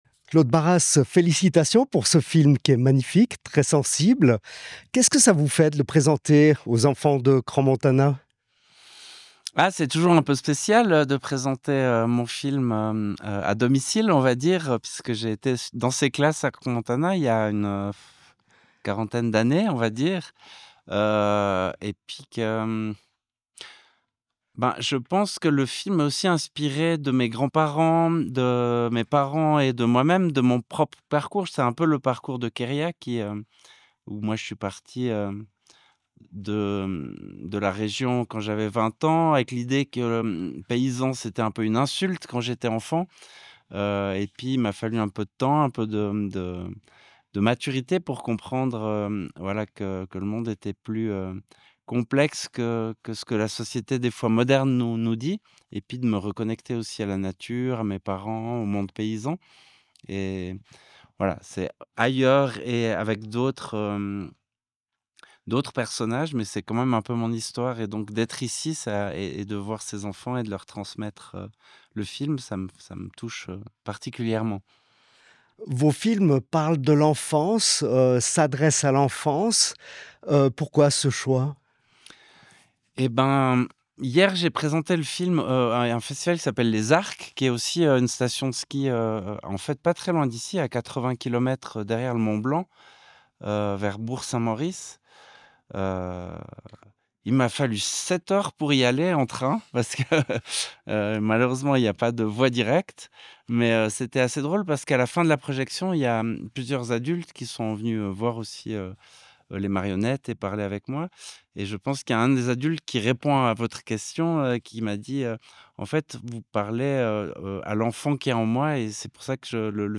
Claude Barras parle de son film "Sauvages" L'INFO a assisté à la projection scolaire de "Sauvages" et a profité de tendre son micro aux différentes personnes présentes.